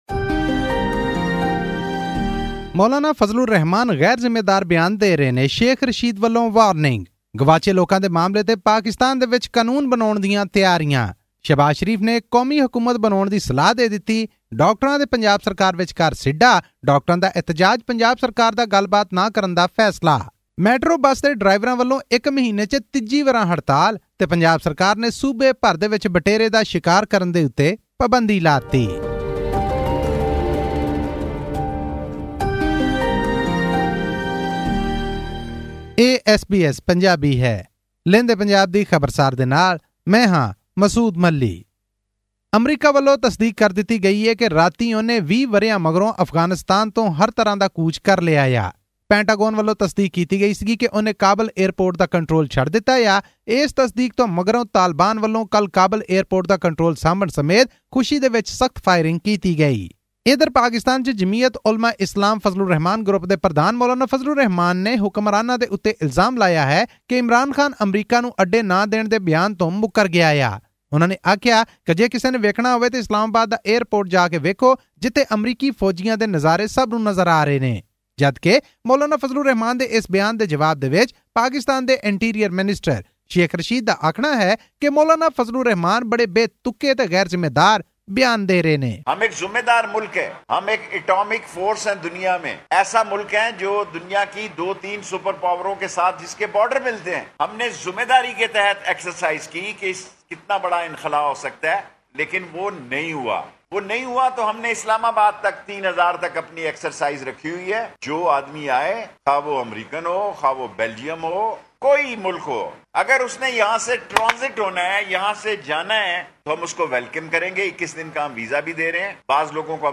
Pakistan’s Interior Minister Sheikh Rashid Ahmad has ruled out a long-term presence of the US troops who came from Afghanistan. His response came after pictures of the troops at Islamabad airport were circulated on social media. All this and more in our weekly news bulletin from Pakistan.